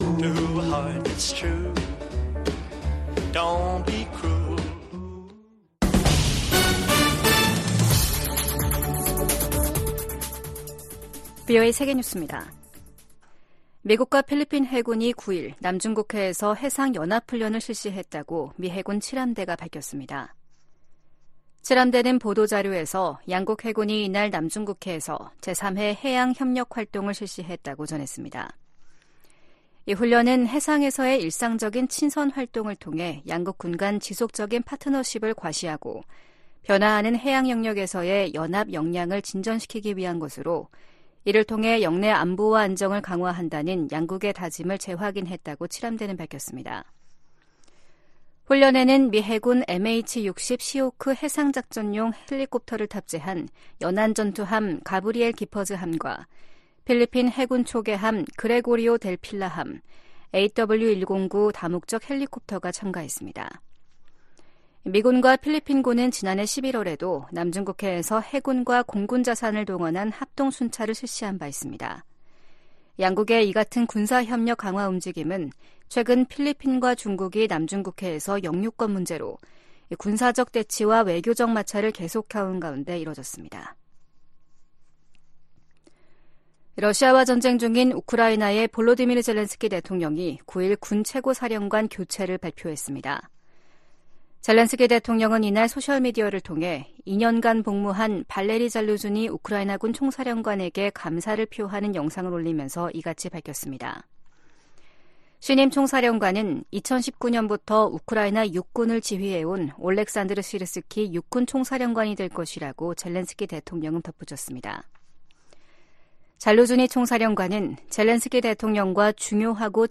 VOA 한국어 아침 뉴스 프로그램 '워싱턴 뉴스 광장' 2024년 2월 10일 방송입니다. 김정은 북한 국무위원장이 조선인민군 창건일인 8일 건군절 국방성 연설에서 한국을 제1 적대국가로 규정했다고 노동신문이 보도했습니다. 미 국무부는 북한 7차 핵실험 가능성을 경고한 주북 러시아 대사의 발언을 불안정하고 위태로우며 위험한 언행이라고 비판했습니다. 일부 전문가들이 한반도 전쟁 위기설을 제기한 가운데 미 국방부는 북한의 임박한 공격 징후는 없다고 밝혔습니다.